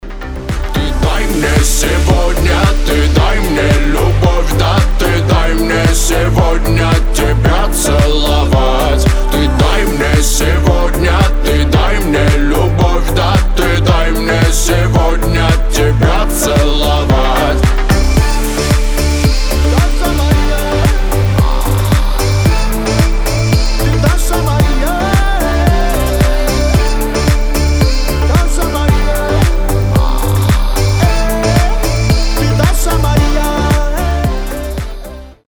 поп
восточные мотивы
Хип-хоп
заводные